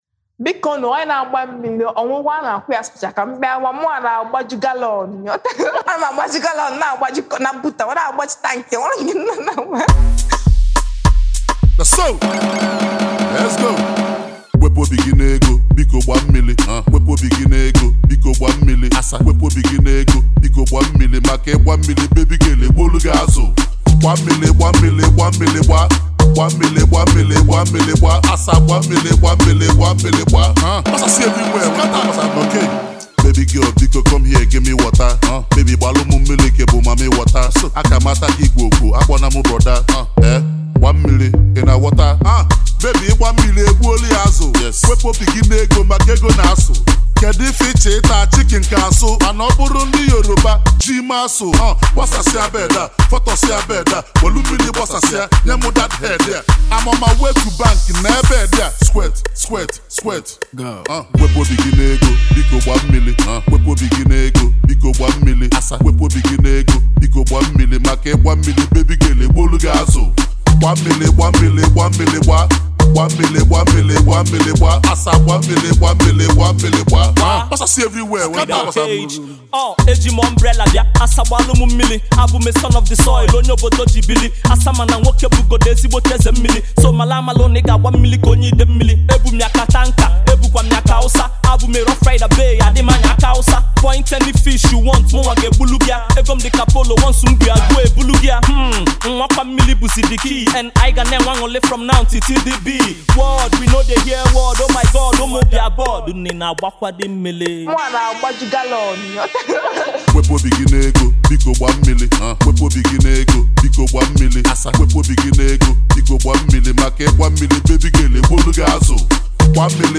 club banger